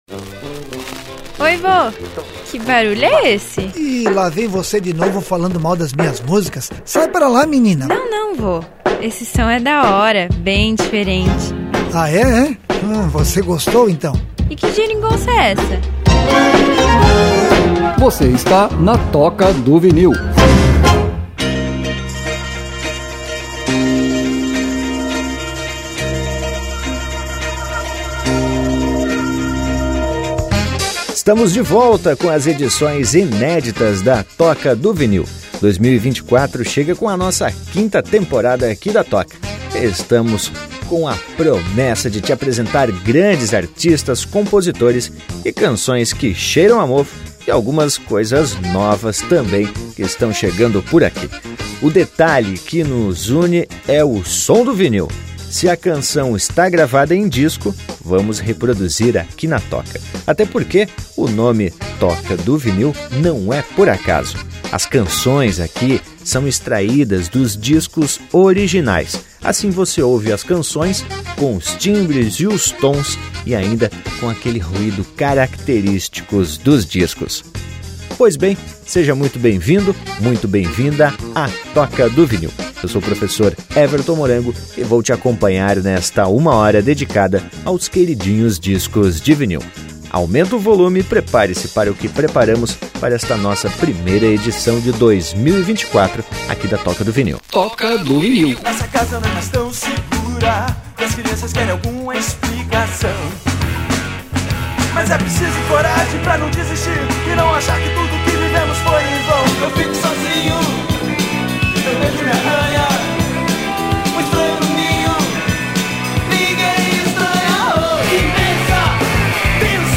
O detalhe que nos une é o som do vinil.